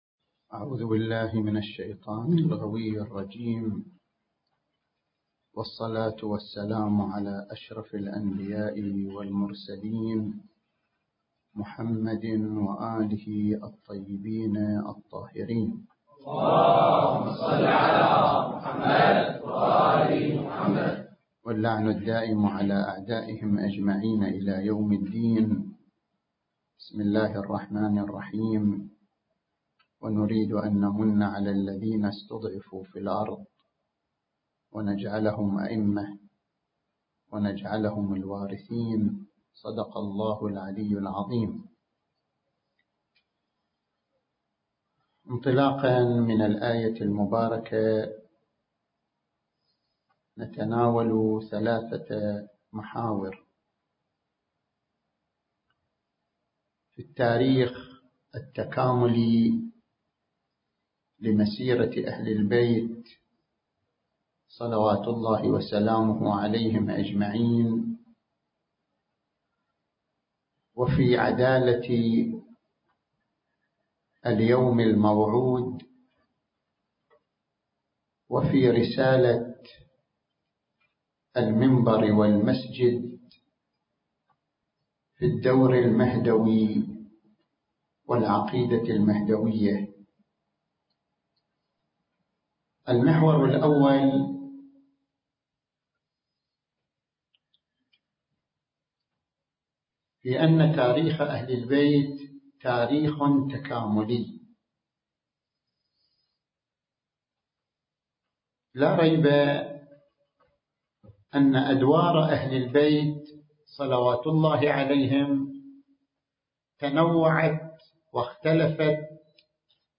المكان: مؤسسة دار الحكمة العالمية